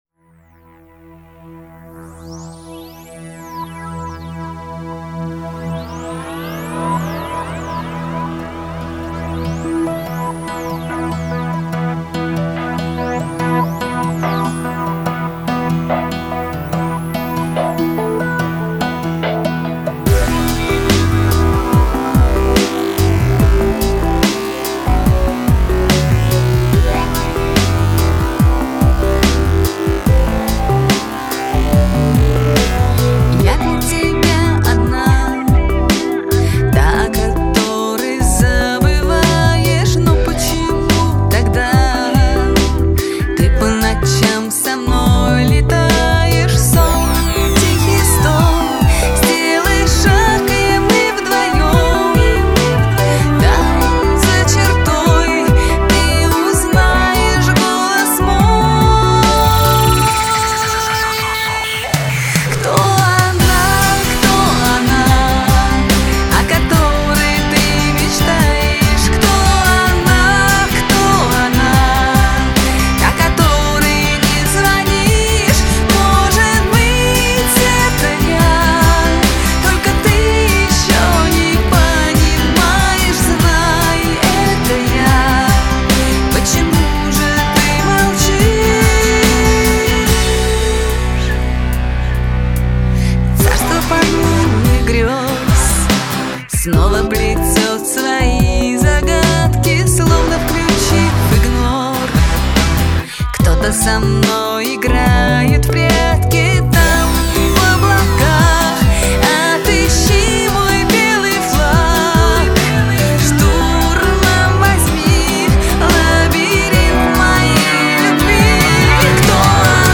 Жанр: Эстрада, Попса